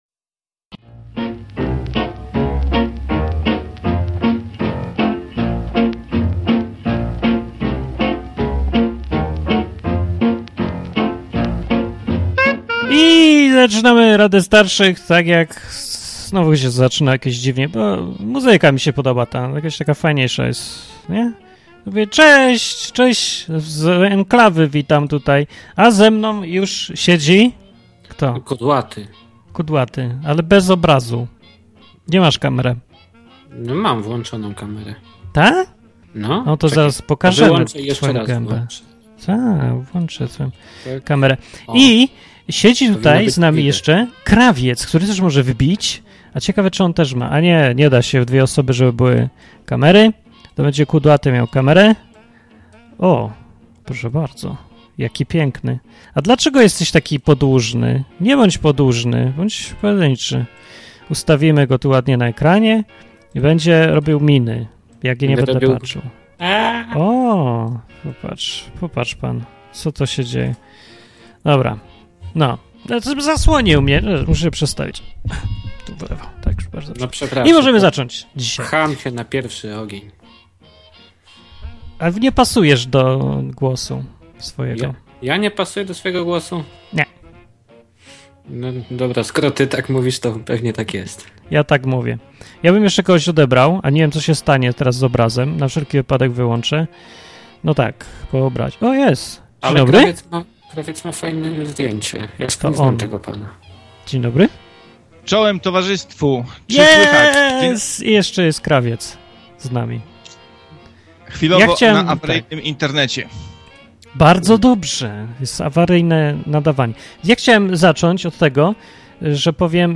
Co tydzień w poniedziałek prowadzący programy w Enklawie zbierają się, aby udzielać słuchaczom rad.